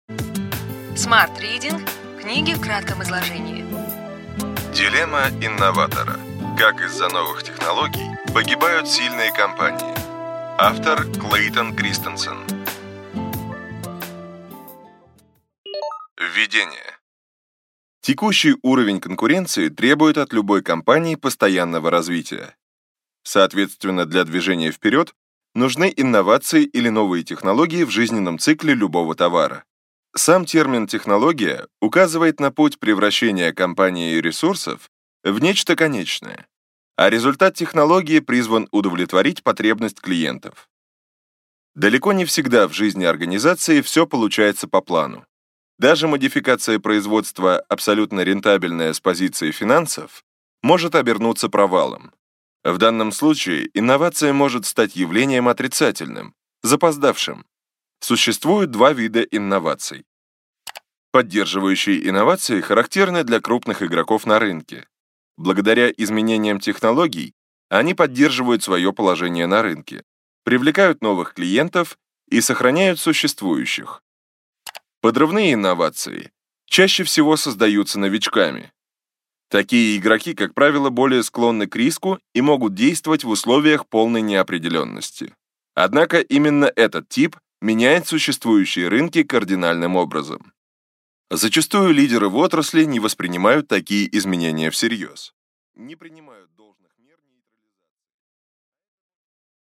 Аудиокнига Ключевые идеи книги: Дилемма инноватора. Как из-за новых технологий погибают сильные компании.